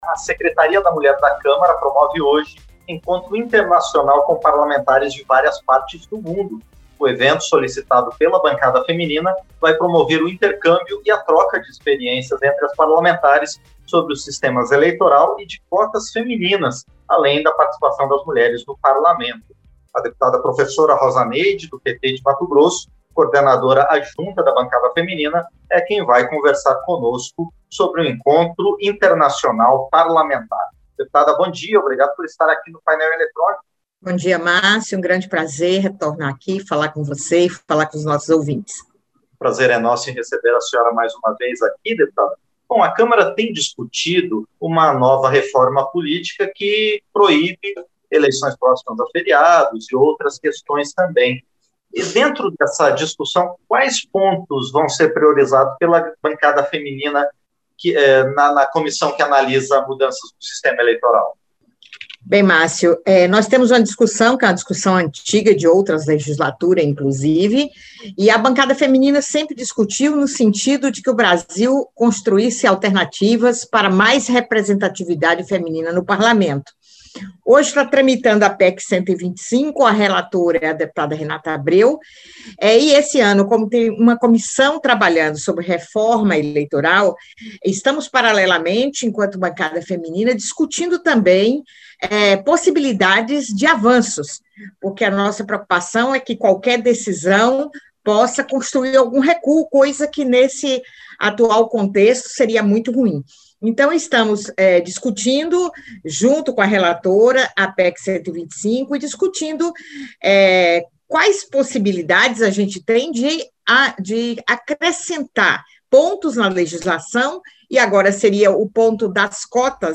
Entrevista - Dep. Professora Rosa Neide (PT-MT)